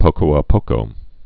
(pōkō ä pōkō)